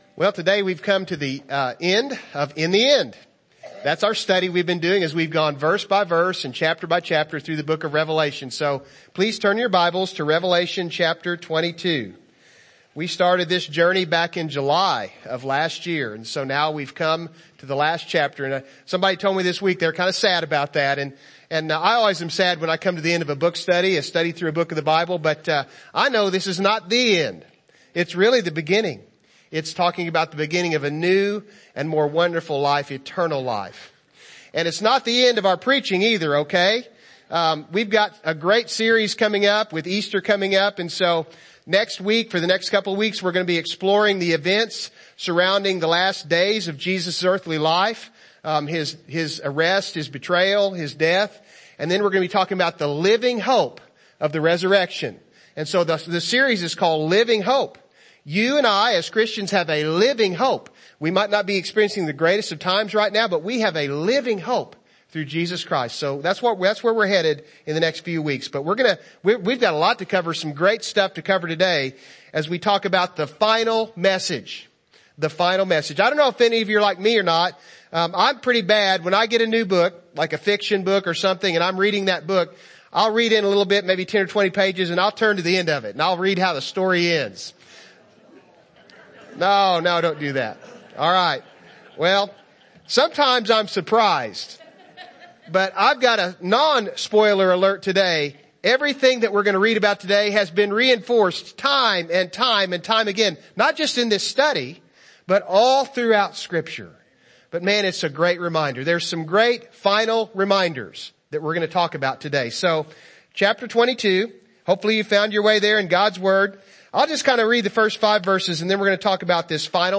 In the End Service Type: Morning Service « Psalms